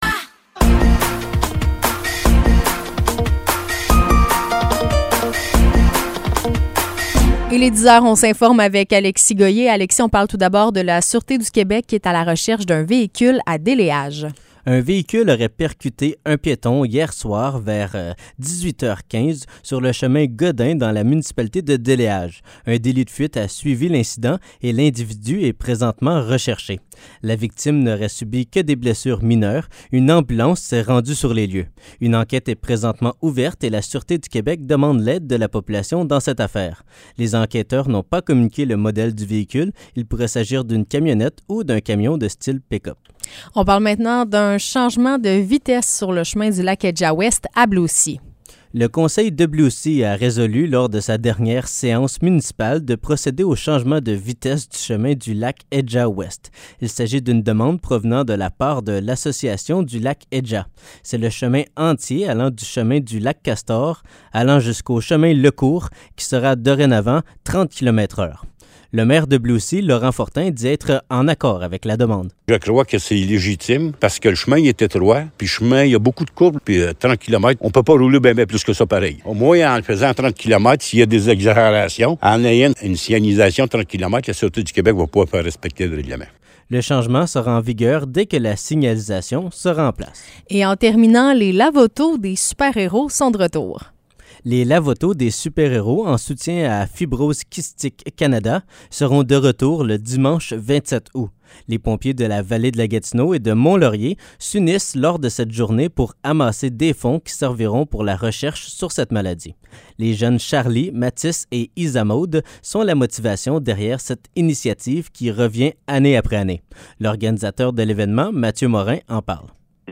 Nouvelles locales - 15 août 2023 - 10 h